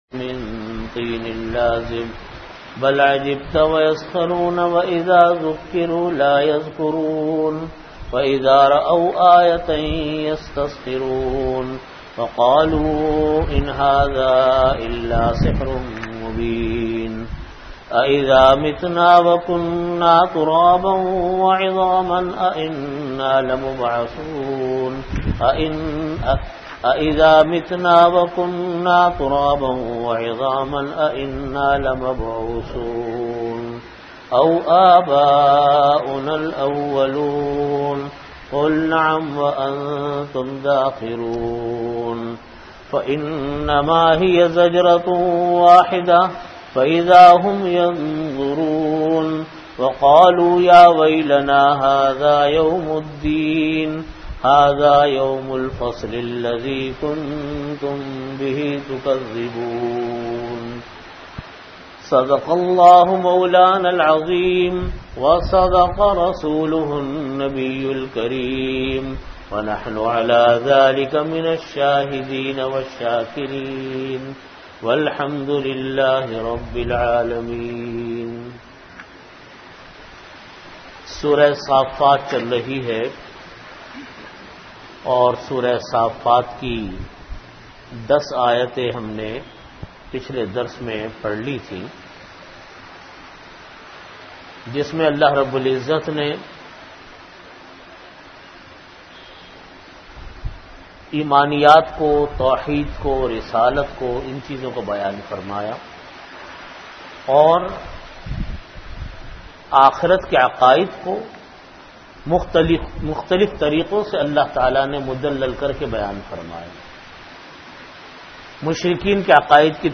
Audio Category: Tafseer
33min Time: After Asar Prayer Venue: Jamia Masjid Bait-ul-Mukkaram, Karachi